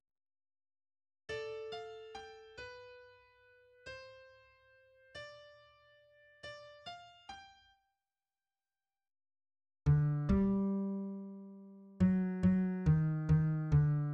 } >> \new Lyrics \lyricsto "three" {\set fontSize = #-2 } \new Staff \with {midiInstrument = #"acoustic bass" instrumentName = "B" shortInstrumentName = "B"} << \set Staff.midiMinimumVolume = #4.7 \set Staff.midiMaximumVolume = #5.9 \set Score.currentBarNumber = #1 \bar "" \tempo 4=70 \time 4/4 \key bes \major \clef bass \new Voice = "four"{ \voiceOne r1 r r2 r4 r8 d g2 fis8 fis ees ees d } >> \new Lyrics \lyricsto "four" {\set fontSize = #-2 oo } >> \midi{}